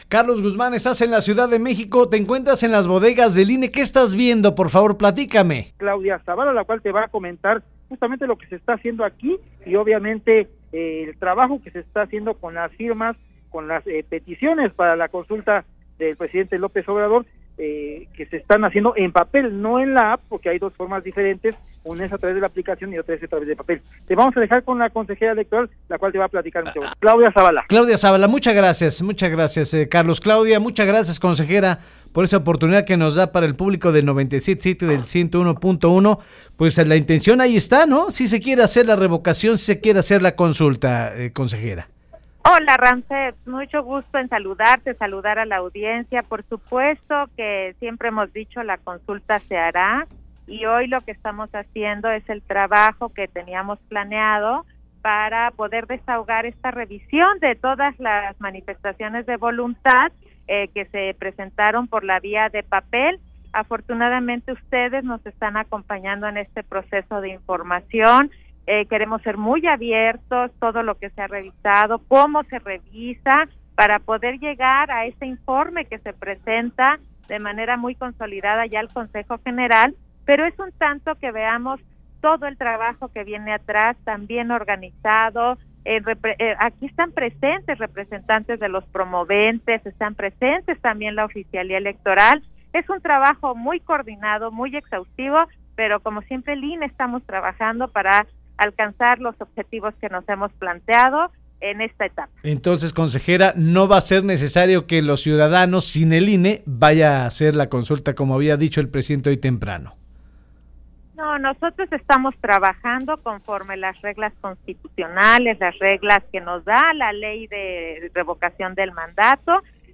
La consejera electoral Claudia Zavala, quien votó a favor de suspender la Consulta de Revocación de Mandato, afirmó en entrevista para En Contacto de Avanoticias, que el Instituto Nacional Electoral no está en contra de la consulta, sin embargo no cuenta con las herramientas para organizar dicha jornada.